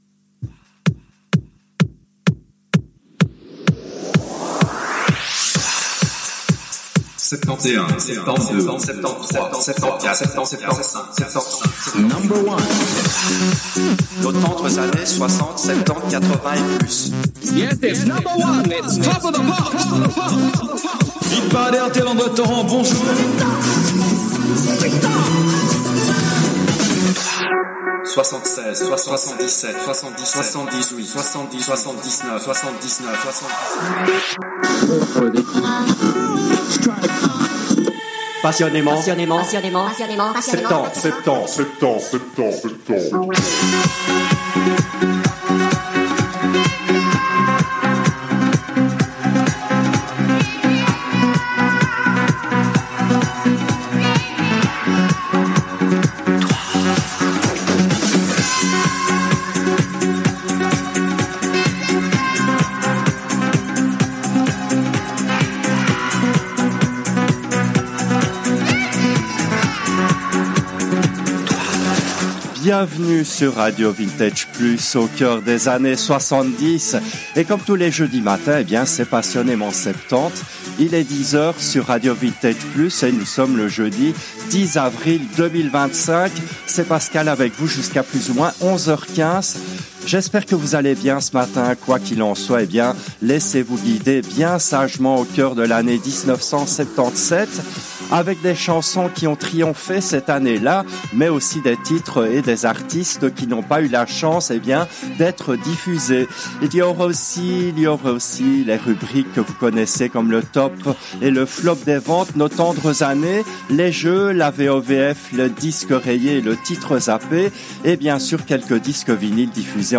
L’émission a été diffusée en direct le jeudi 10 avril 2025 à 10h depuis les studios belges de RADIO RV